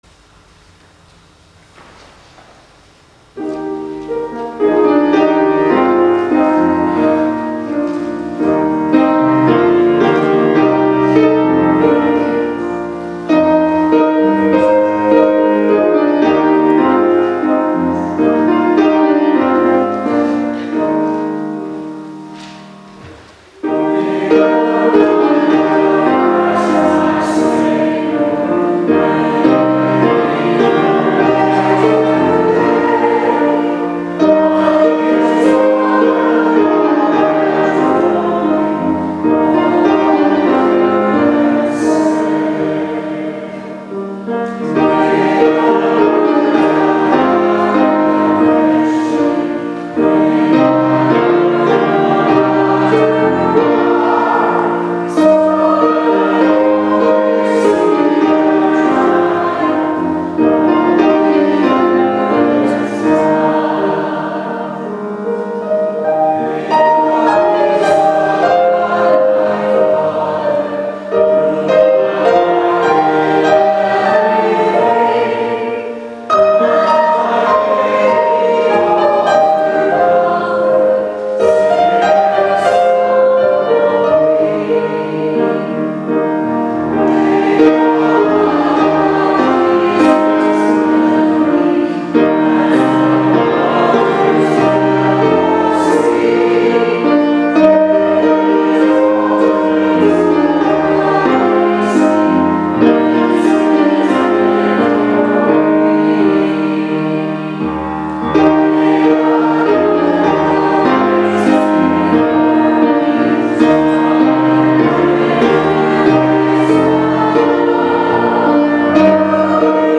Closing Hymn “May the Mind of Christ My Savior”